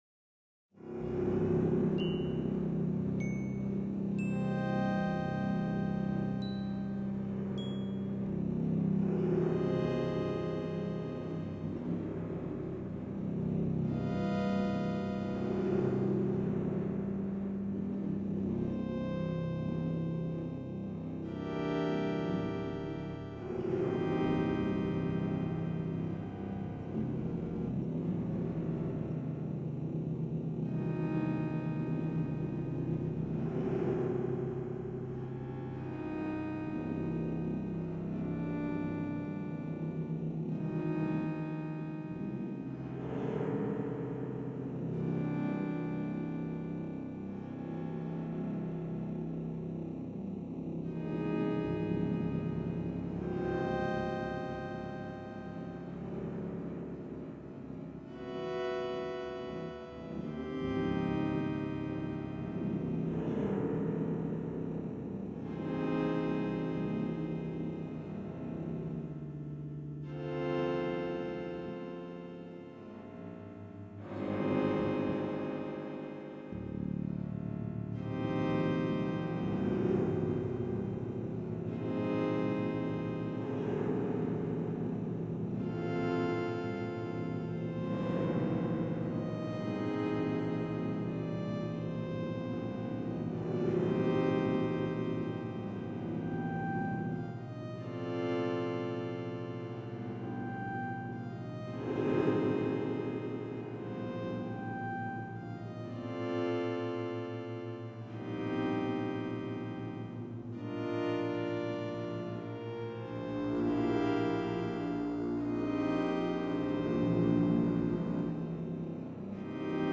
BGM Music for Ruins Area of Spoak Homeworld